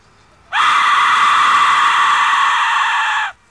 Sound Buttons: Sound Buttons View : Aztec Death Whistle
death-whistle-57.mp3